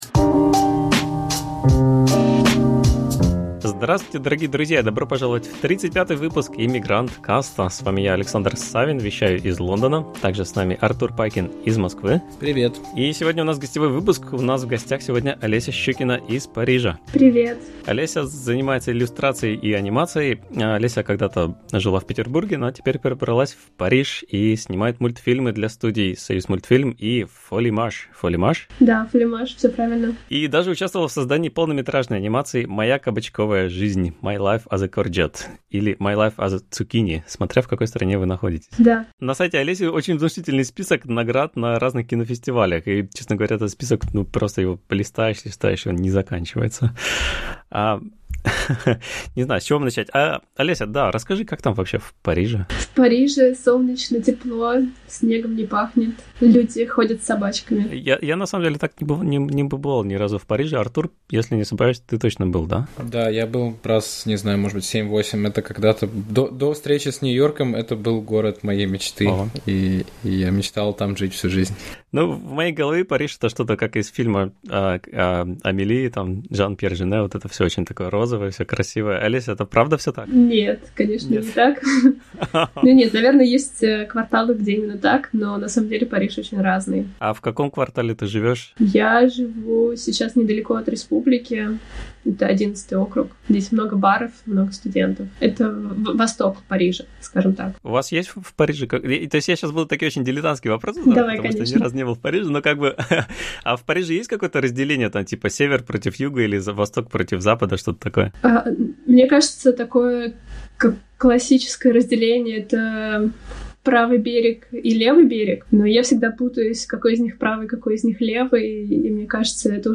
Подкаст о жизни в Лондоне, Берлине и Нью-Йорке, пленочной фотографии, инди-интернете, лодках, брекзите и дронах. На проводе гости от Сингапура и Гоа до Франции и США — от дизайнера шрифтов до пилота самолета.